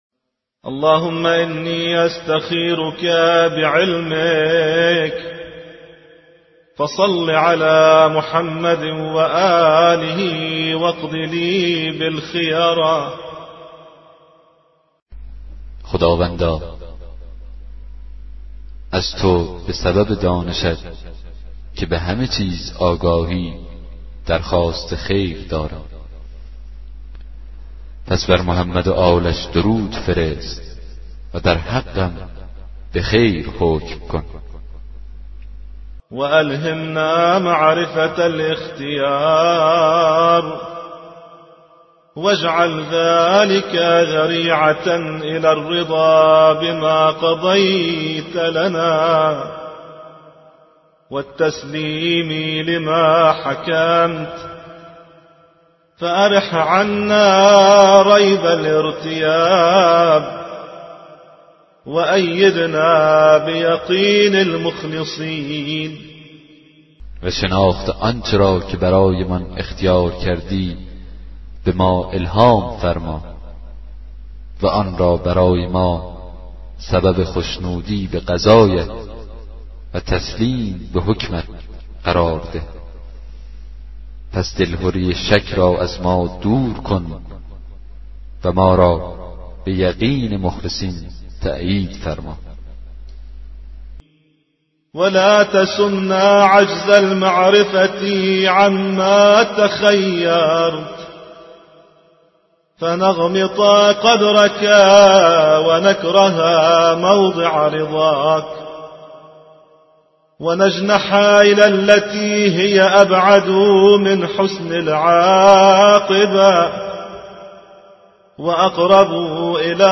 کتاب صوتی دعای 33 صحیفه سجادیه